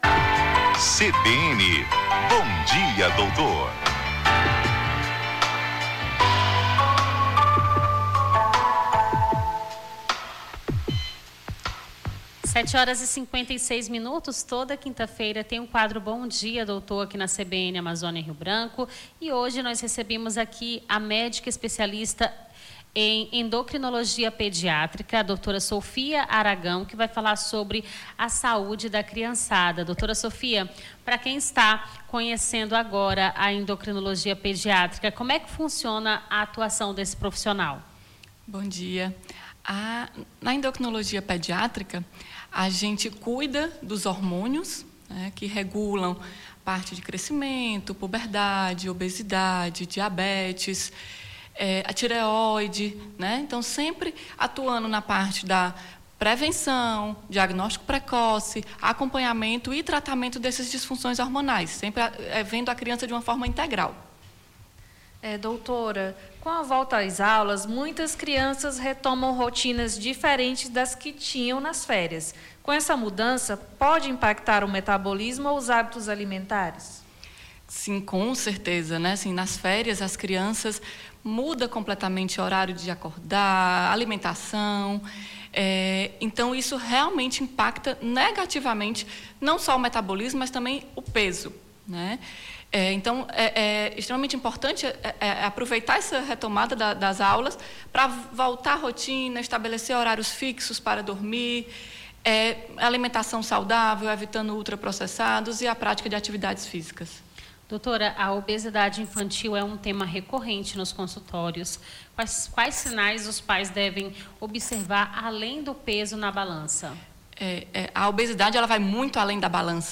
Bom Dia Doutor: especialista em endocrinologia fala sobre saúde infantil